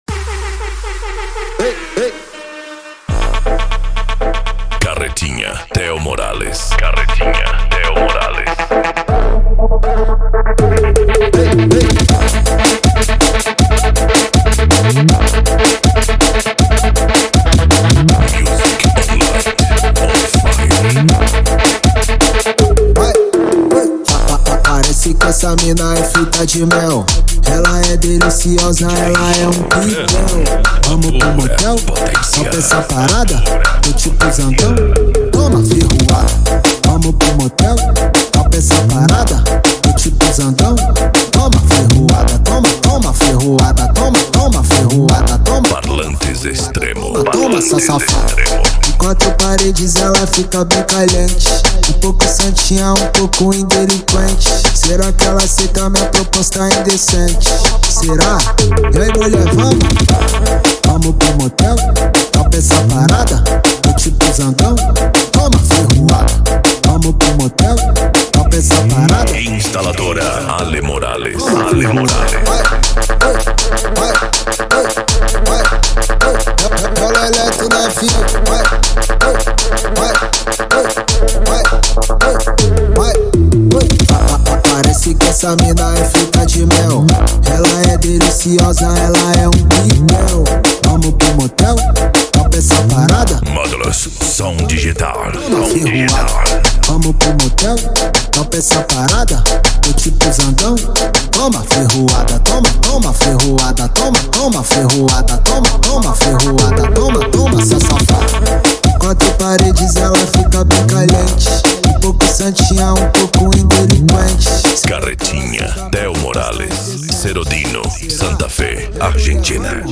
Psy Trance
Racha De Som
Remix